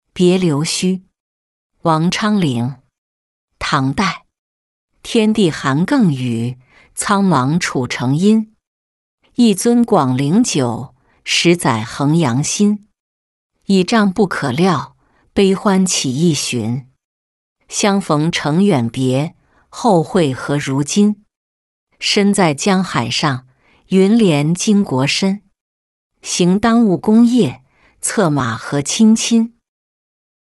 别刘谞-音频朗读